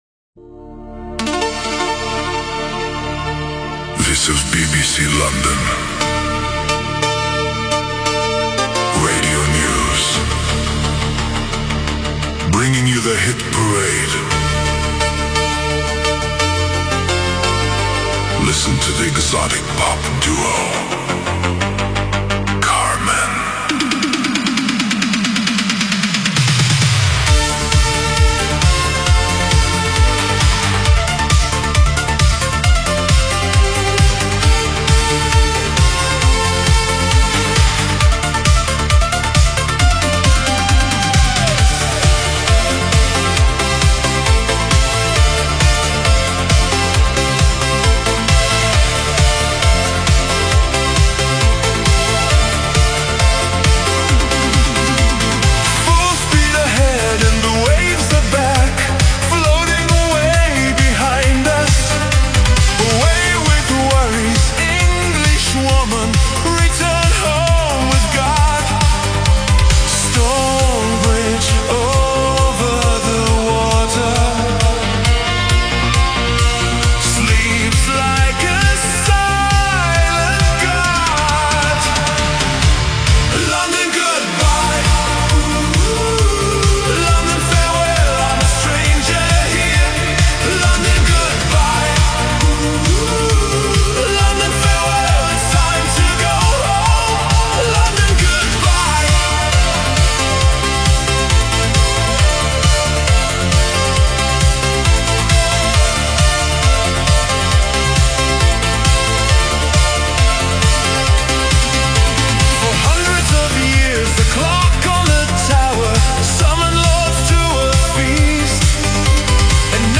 exotic pop duo